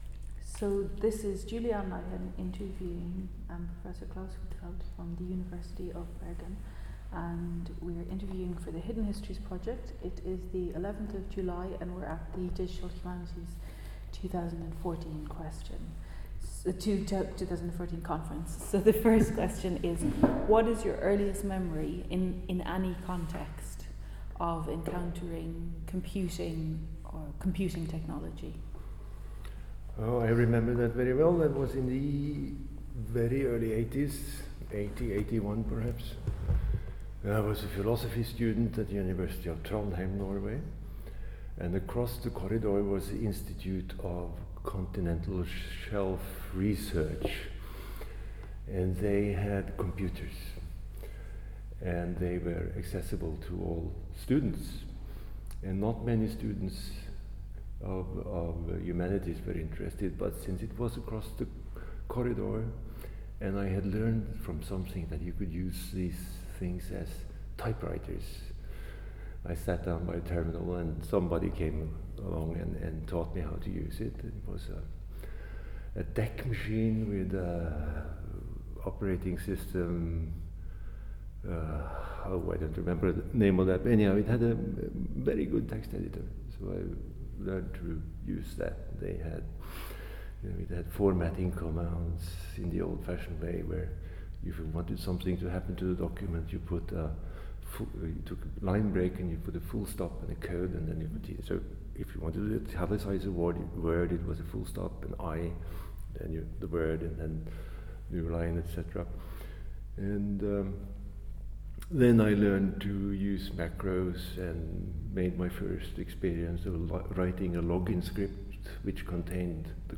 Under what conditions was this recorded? This interview was conducted on 11 July at the 2014 Digital Humanities Conference, Lausanne, Switzerland.